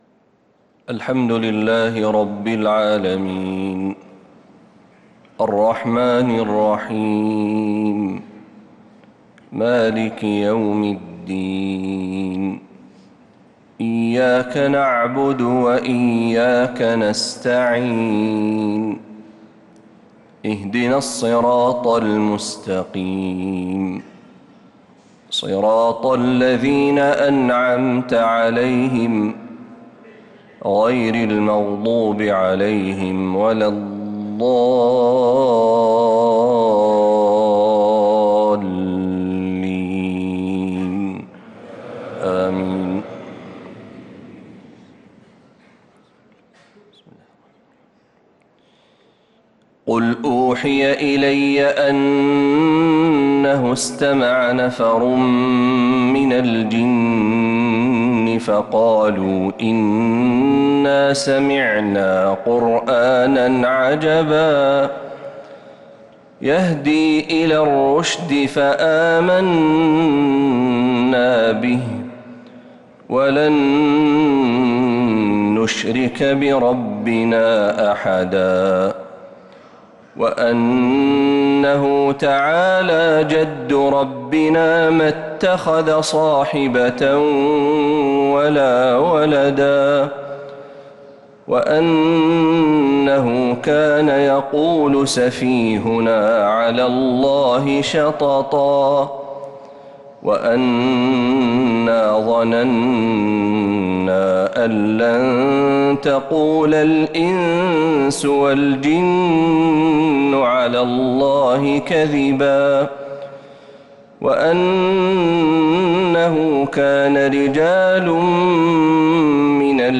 فجر الثلاثاء 7-7-1446هـ سورة الجن كاملة | Fajr prayer Surat al-Jinn 7-1-2025 > 1446 🕌 > الفروض - تلاوات الحرمين